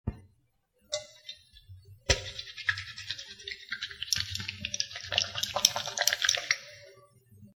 Lavar frotando con agua y jabón
Grabación sonora en la se escucha como alguien comienza a lavar algo, posiblemente con agua y jabón, frotando efusivamente.
Sonidos: Acciones humanas
Sonidos: Hogar